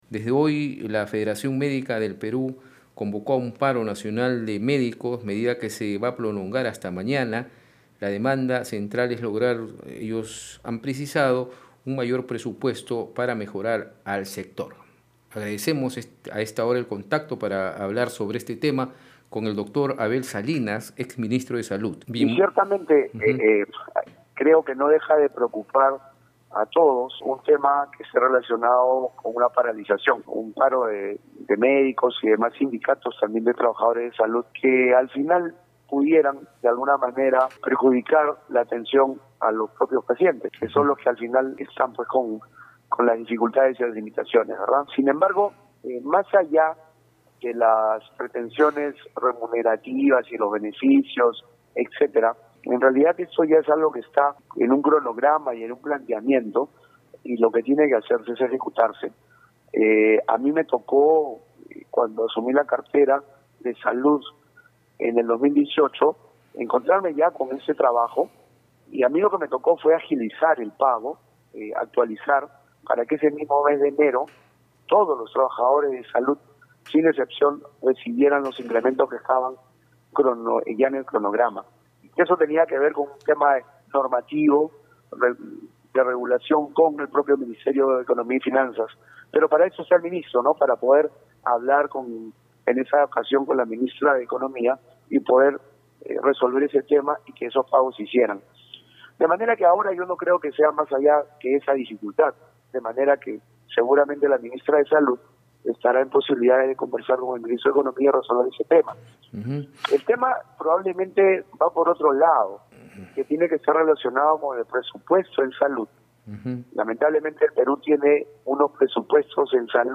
En declaraciones a Red de Comunicación Regional, detalló que el gasto público en salud en el país llega a 2,3 por ciento del Producto Bruto Interno (PBI), mientras que en otros países de la región, se ubica por encima del 4 por ciento.